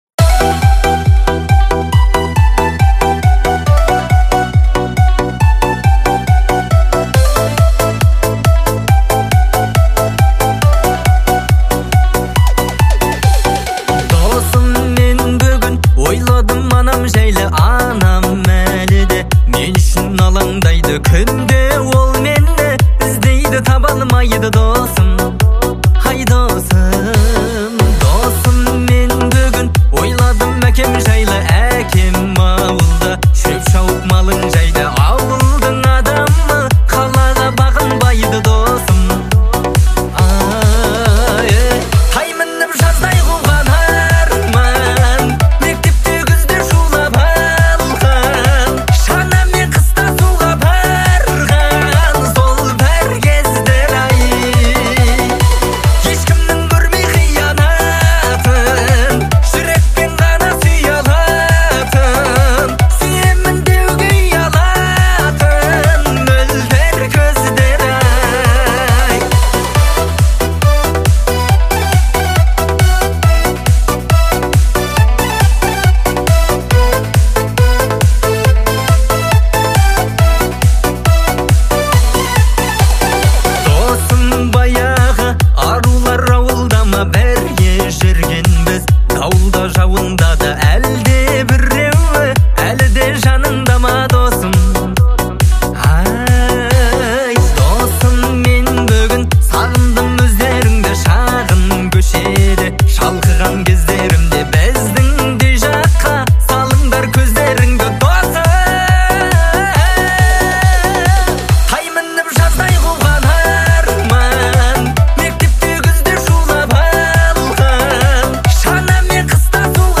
Казахская